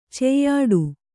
♪ ceyyāḍu